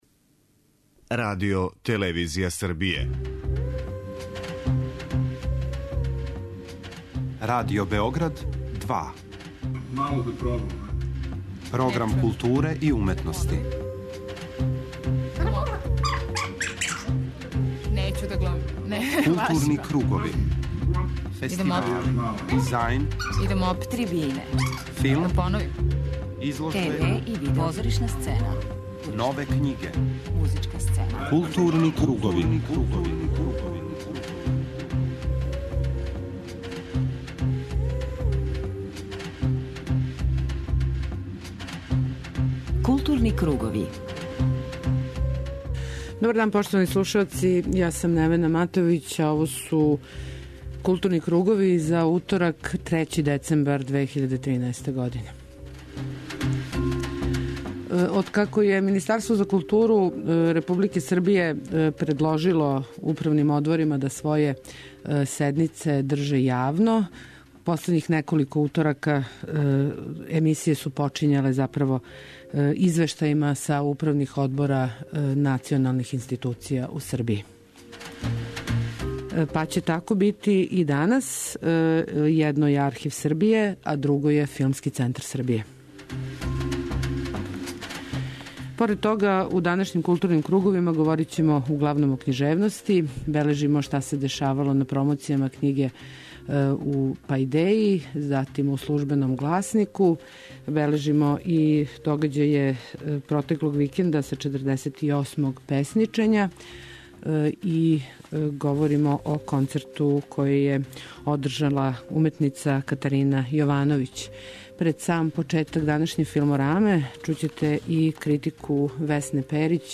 У разговору који ћете чути, за нашу емисију редитељ говори о својим јунацима, филмовима, начину рада, стрпљењу и "невидљивости", толико потребној да би се снимио добар докуметарни филм.